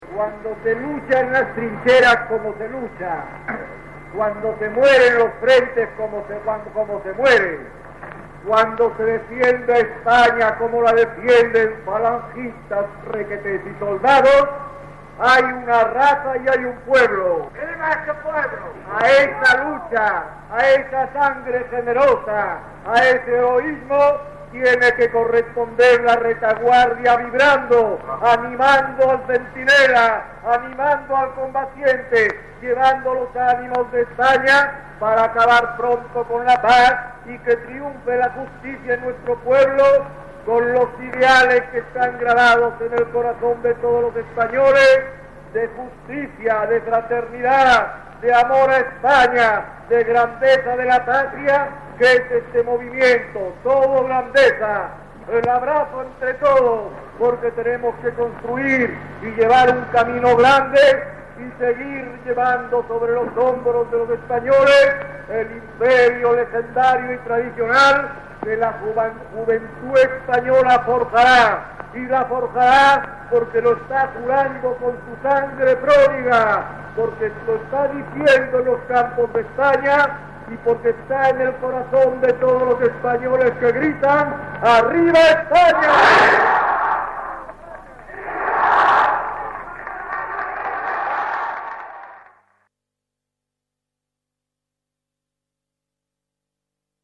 Discurso a las Tropas Nacionales (1938).
Francisco Franco-Discurso a las tropas nacionales (1938).mp3